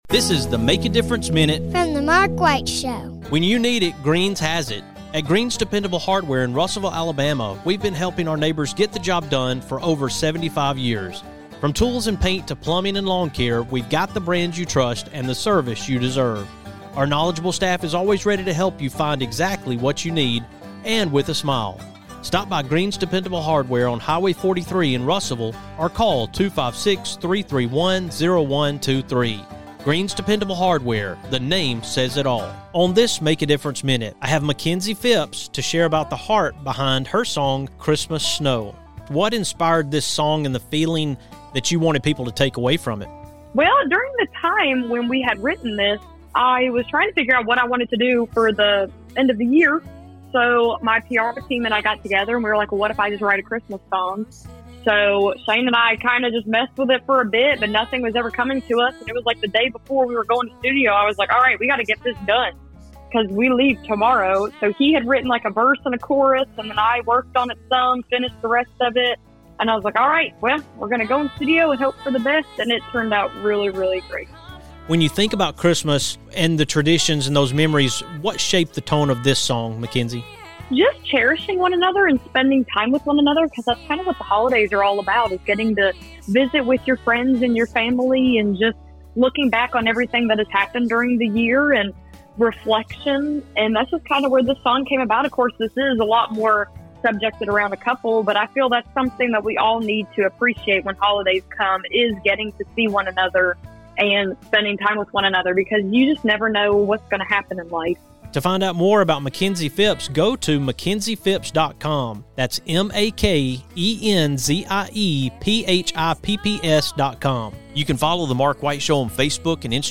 Tune in for a beautiful moment of music and message.